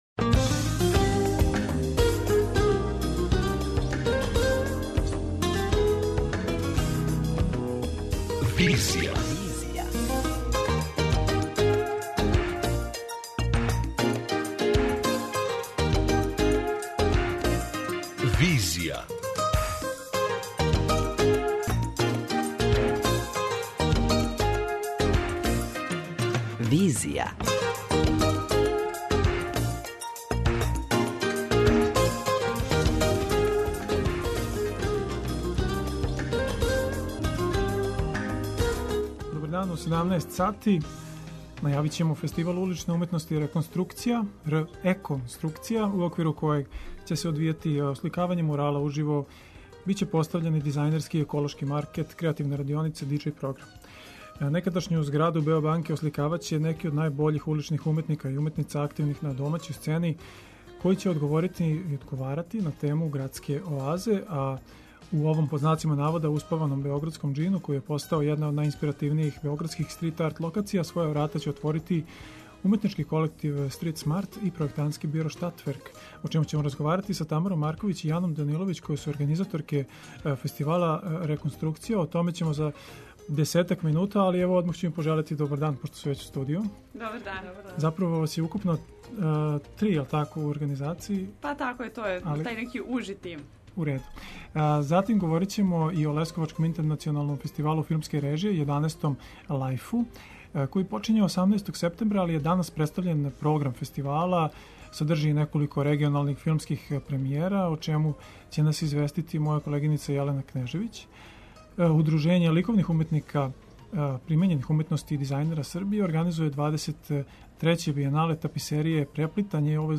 преузми : 55.91 MB Визија Autor: Београд 202 Социо-културолошки магазин, који прати савремене друштвене феномене.